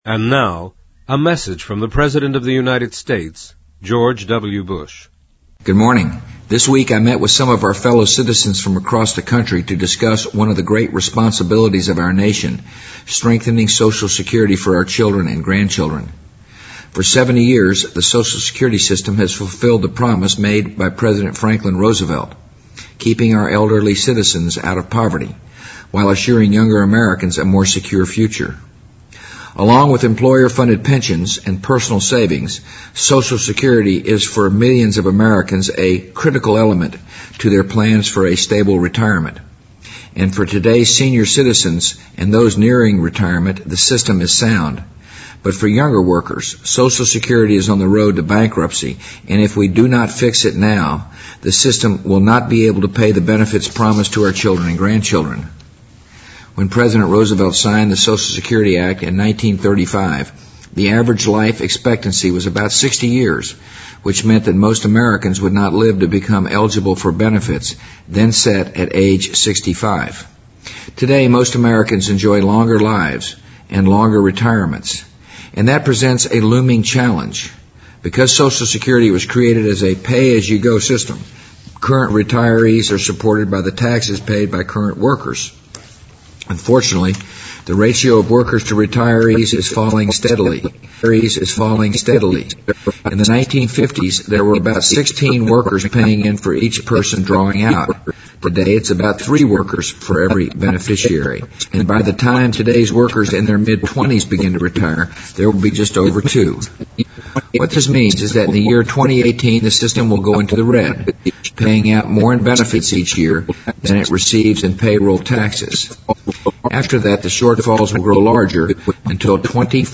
President Bush-2005-01-15电台演说 听力文件下载—在线英语听力室